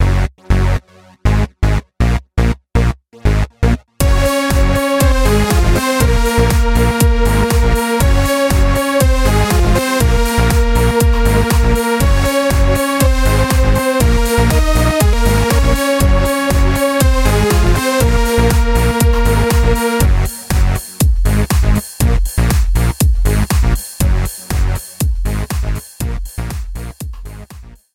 dance remix